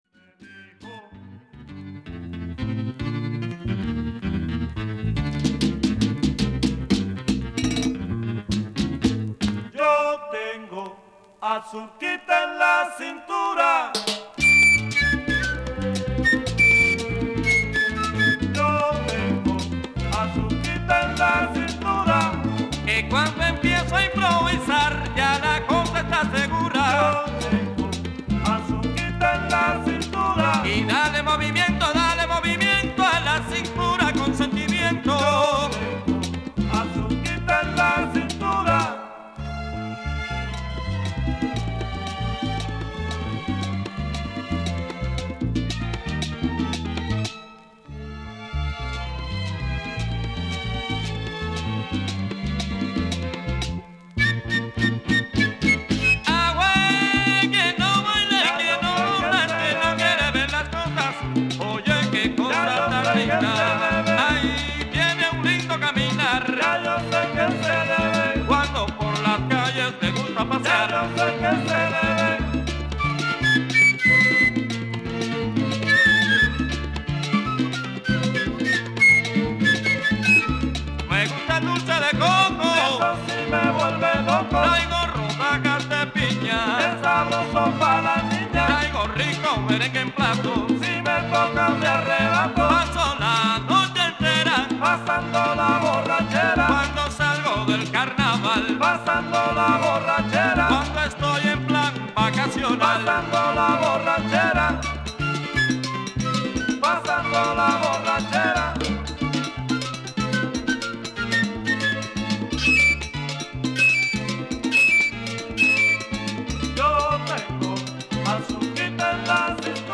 Charanga
Guaracha